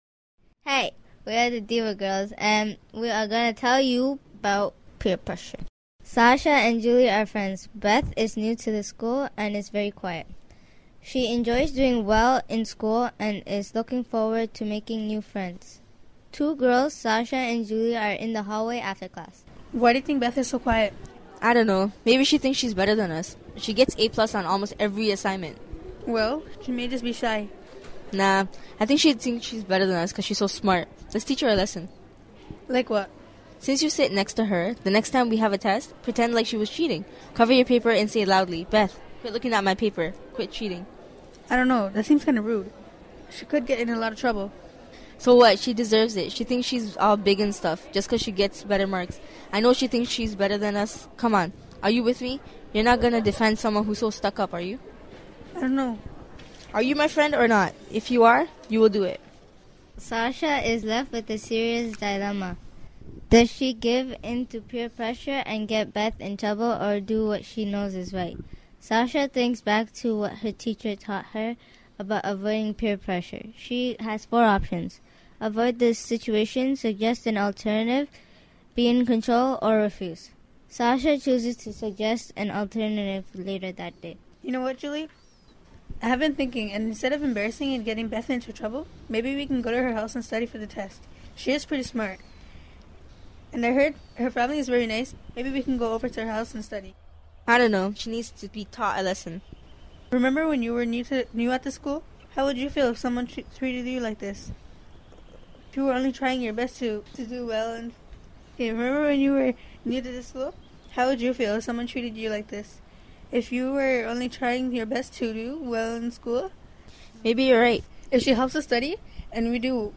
The following are radio works that were scripted and produced by young people involved in the Diva's Young Women's Media Program.
Peer Pressure An audio dramatization about peer pressure.